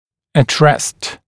[ət rest][эт рэст]в состоянии покоя